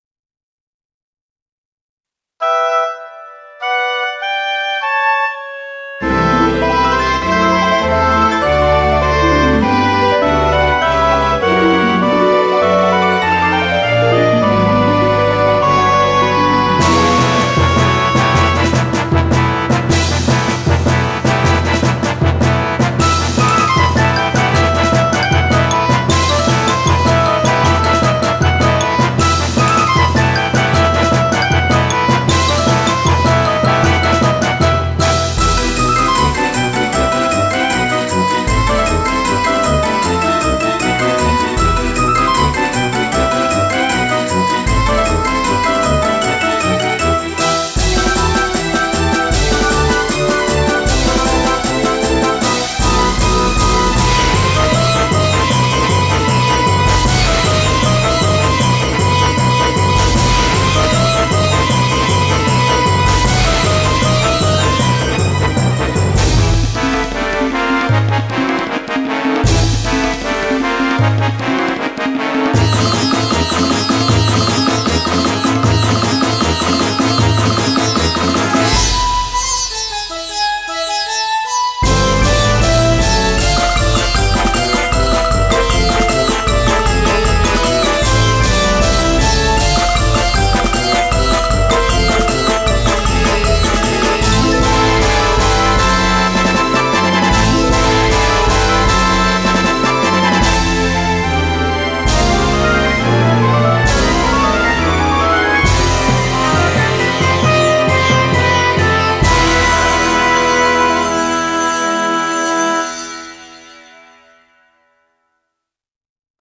BPM100-155
Audio QualityPerfect (Low Quality)